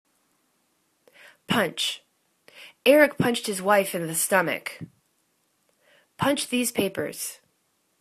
punch     /punch/    v